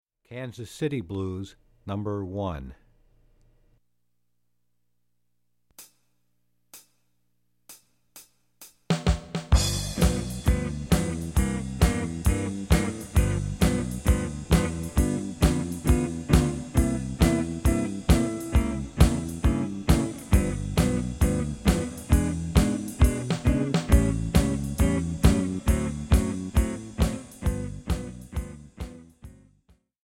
Guitare Tablatures